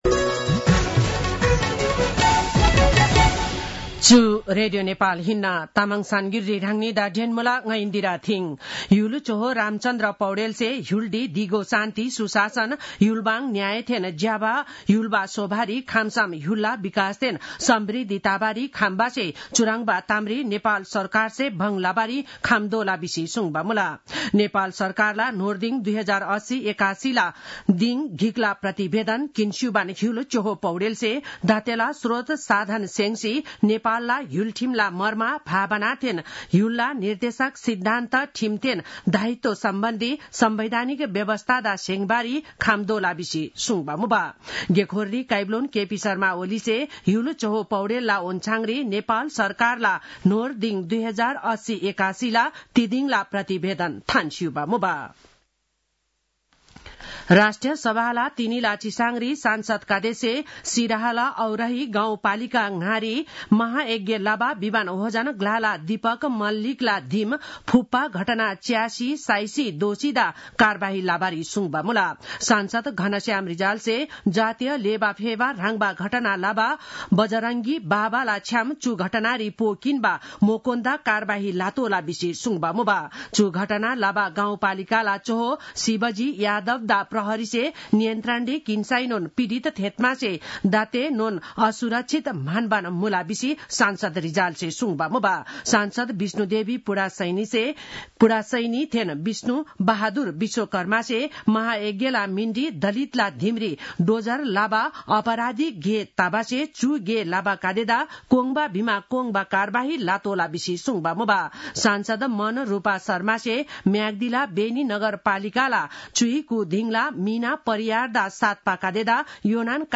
तामाङ भाषाको समाचार : १० चैत , २०८१